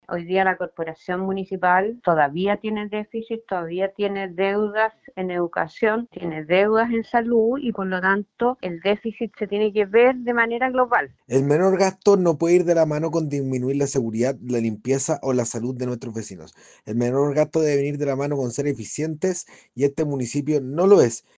En el Concejo, los ediles Antonella Pechenino y Andrés Solar valoraron el descenso del déficit.